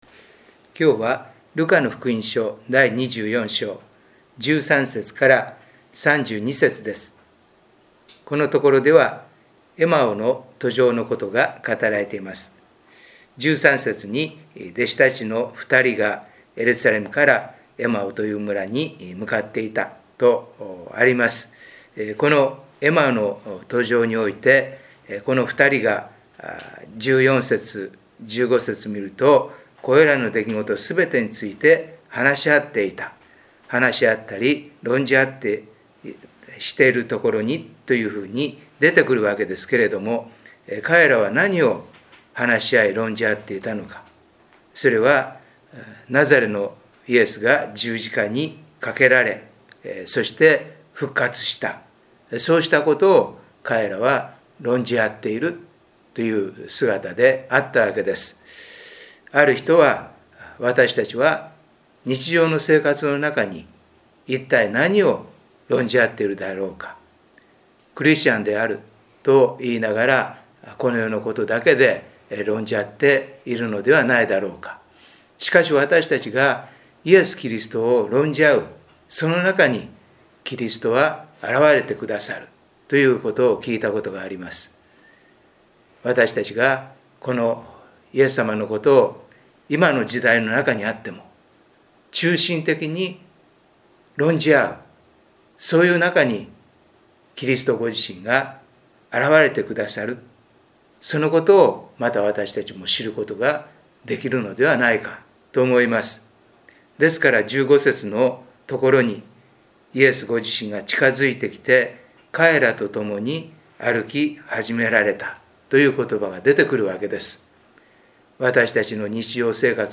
礼拝メッセージ│日本イエス・キリスト教団 柏 原 教 会